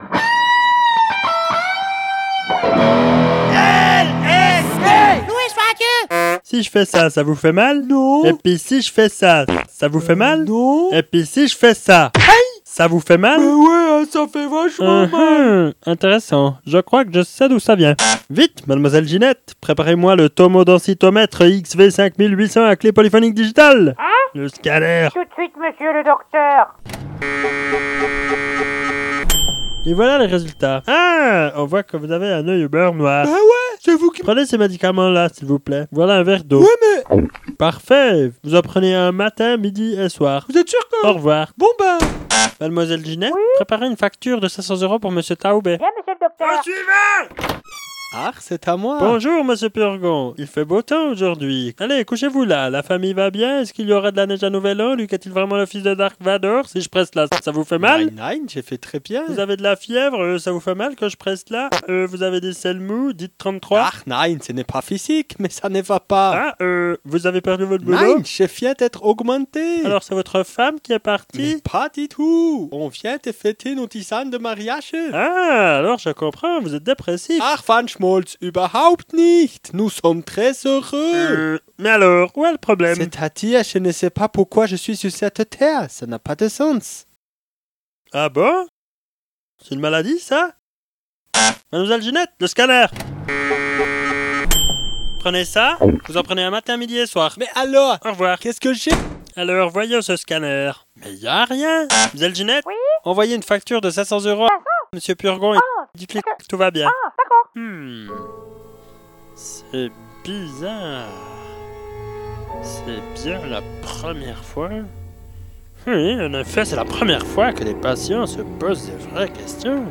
Les sketches :